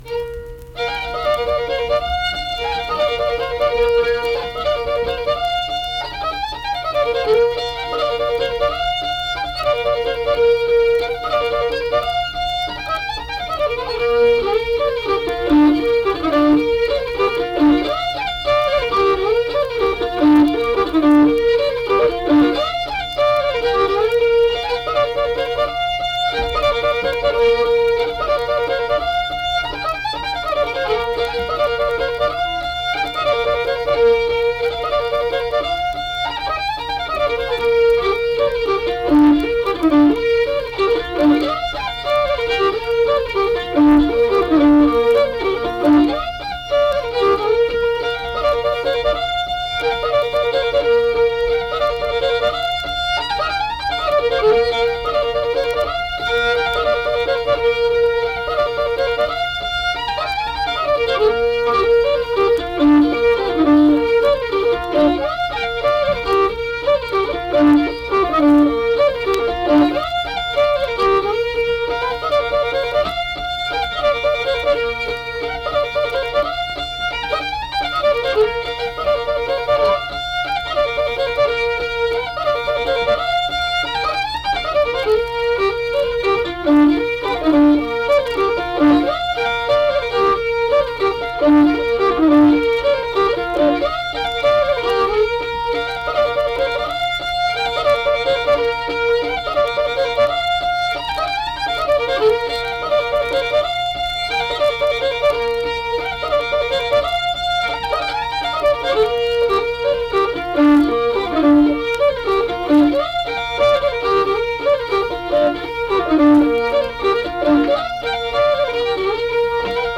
Unaccompanied fiddle music and accompanied (guitar) vocal music performance
Instrumental Music
Fiddle
Braxton County (W. Va.)